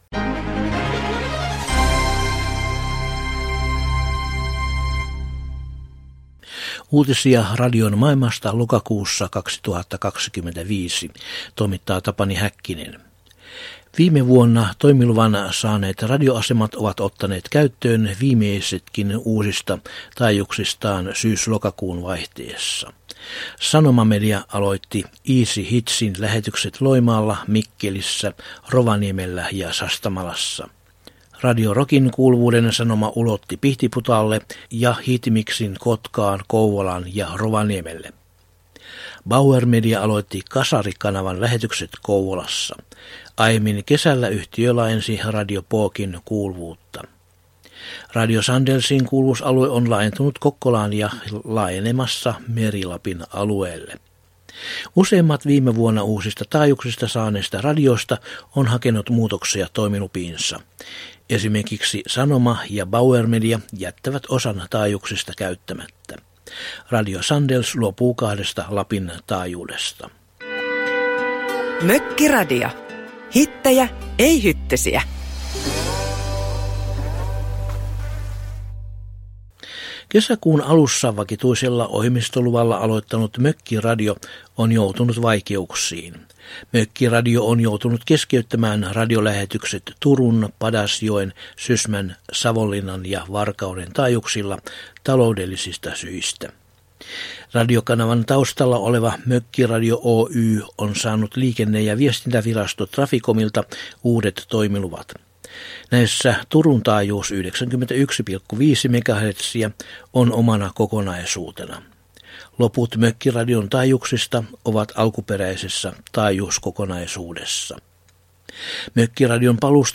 Lokakuun 2025 uutislähetyksessä aiheina ovat muun muassa vuoden 2024 radiotoimilupakierroksella myönnettyjen taajuuksien käyttöönotto, Savon Aaltojen uusi taajuus Kuopiossa ja kotimaisten lyhytaaltoradioiden aktiivisuus loppusyksyn aikana.